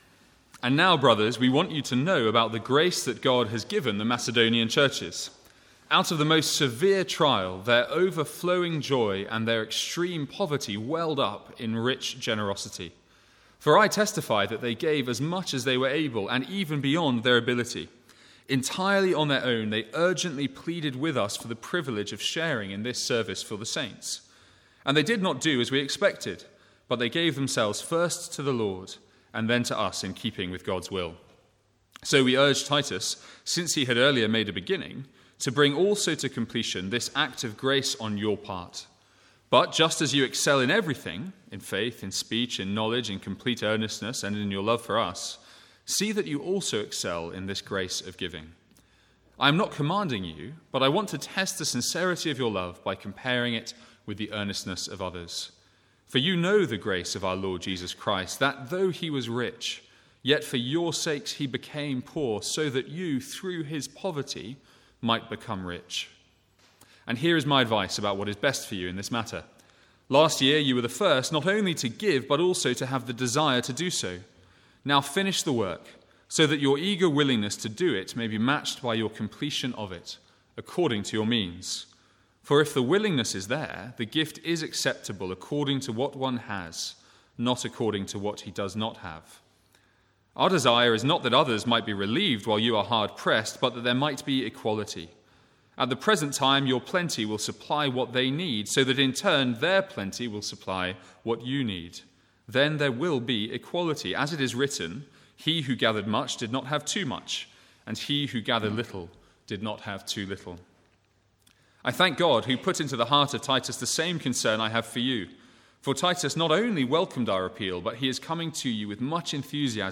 Sermons | St Andrews Free Church
From the Sunday morning series in 2 Corinthians.